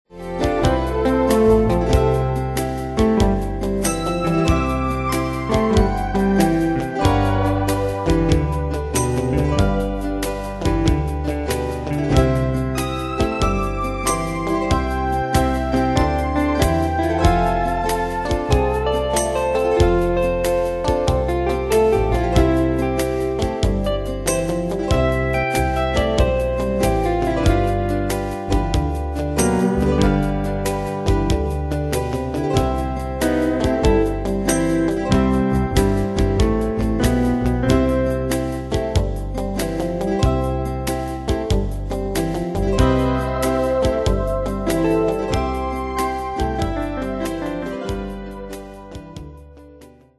Style: Country Ane/Year: 1981 Tempo: 94 Durée/Time: 3.17
Danse/Dance: TwoSteps Cat Id.
Pro Backing Tracks